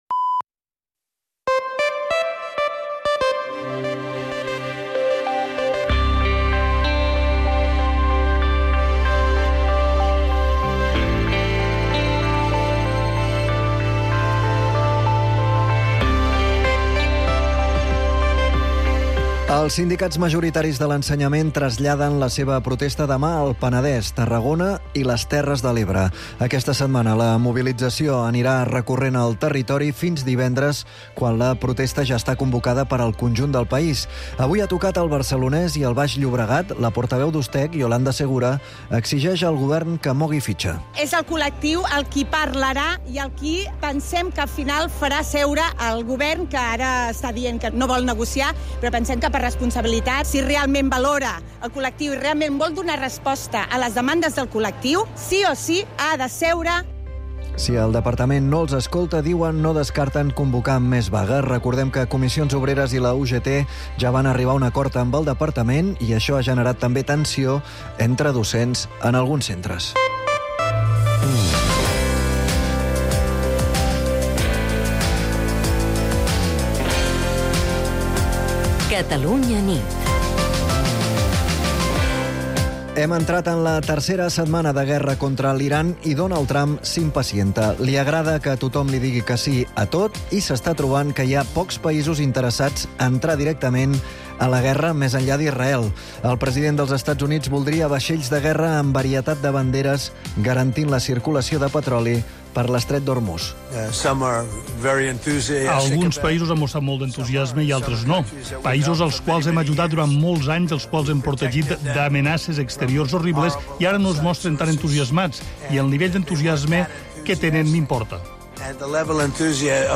El compromís d'explicar tot el que passa i, sobretot, per què passa és la principal divisa del "Catalunya nit", l'informatiu nocturn de Catalunya Ràdio, dirigit per Manel Alías i Agnès Marquès.